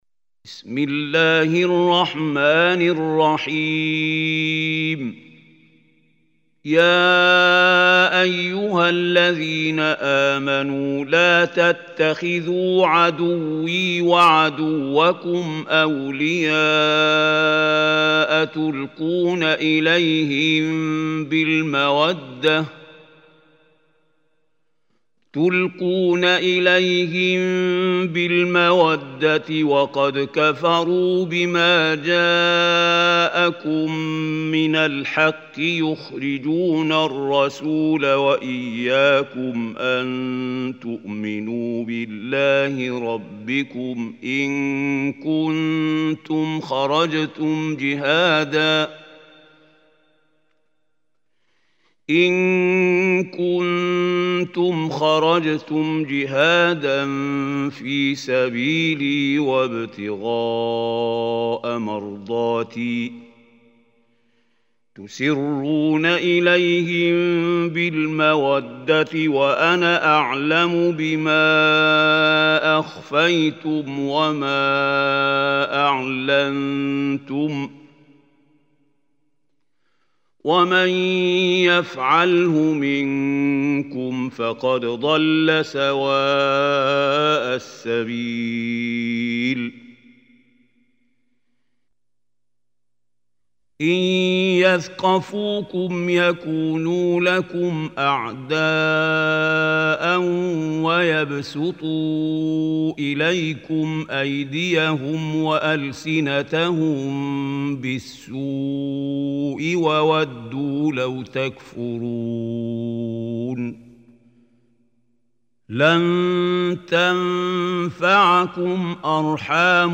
Surah Mumtahanah Recitation by Mahmoud Hussary
Surah Mumtahanah is 60 surah of Holy Quran. Listen or play online mp3 tilawat / recitation in Arabic in the beautiful voice of Mahmoud Khalil Hussary.